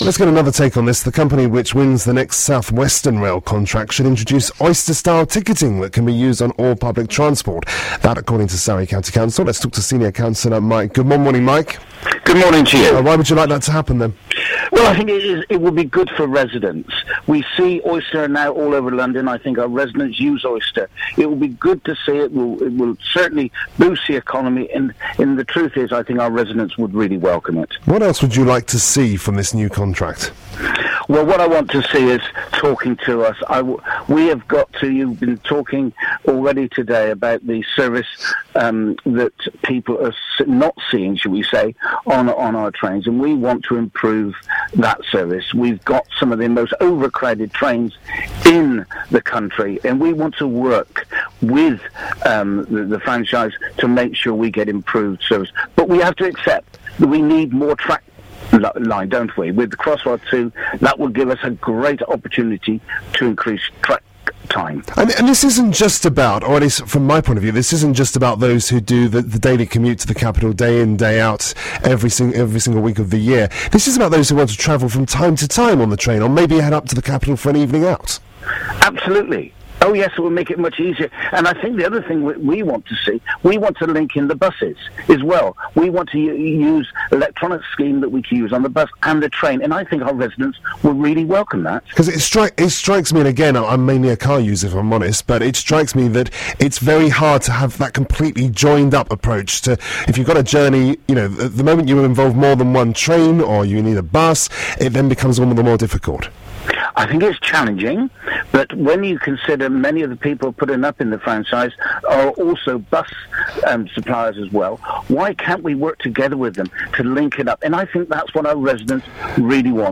BBC Surrey interviews Mike Goodman about Oyster-style ticketing